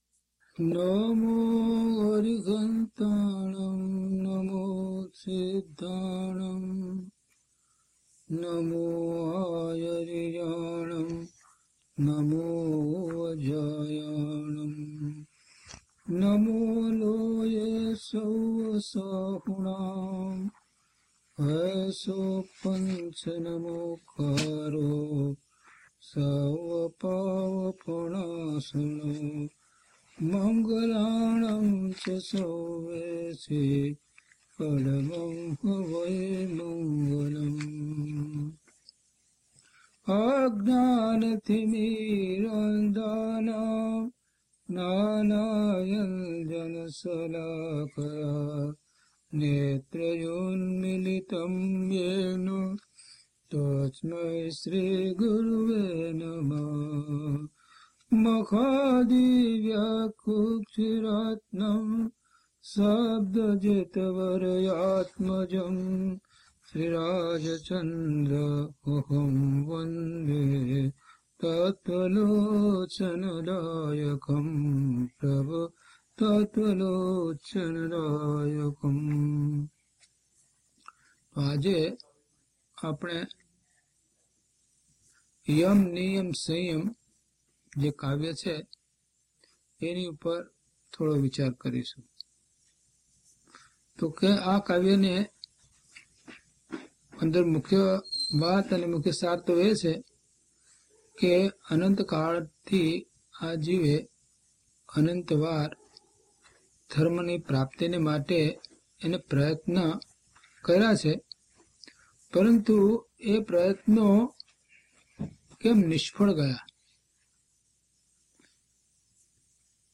DHP002 Yam Niyam Sanyam Aap Kiyo - Pravachan.mp3